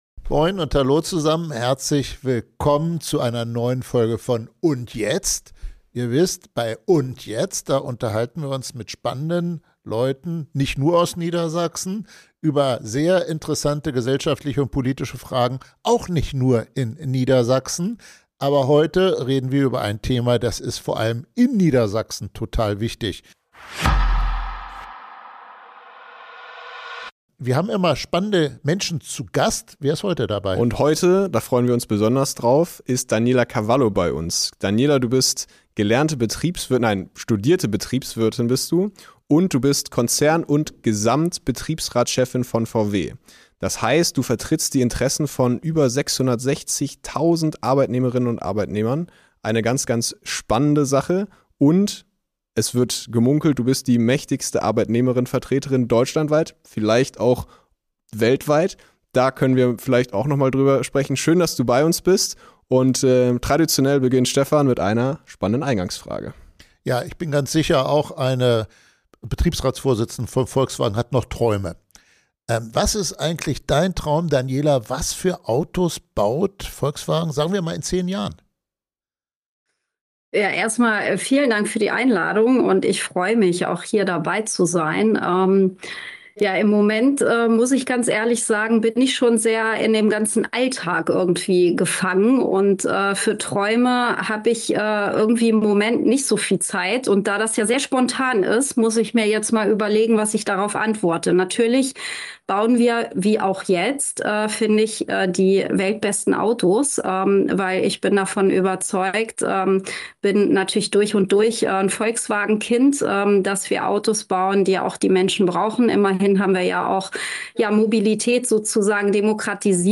Ein intensives Gespräch über Transformation, Industriepolitik und die unbequeme Realität, dass sich eine ganze Branche neu erfinden muss.